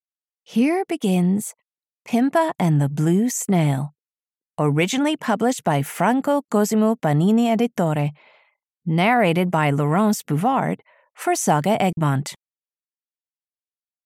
Pimpa and the Blue Snail (EN) audiokniha
Ukázka z knihy